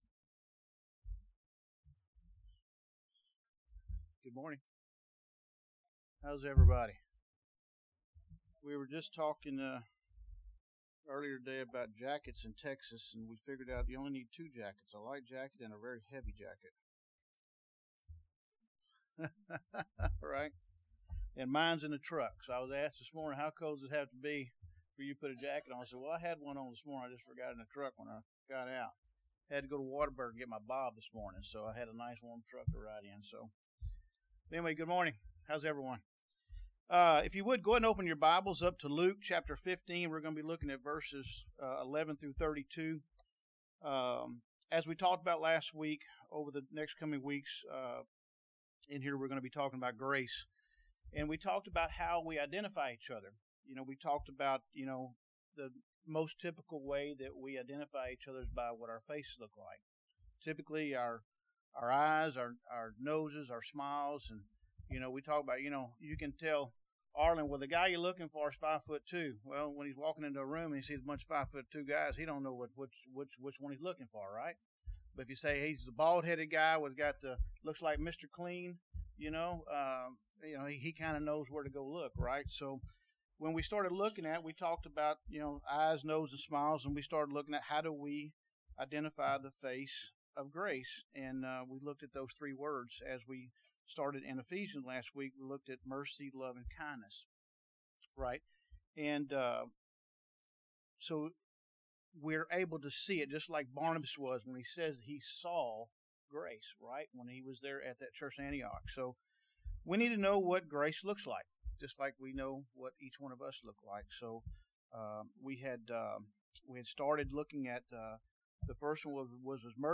Sunday Bible Class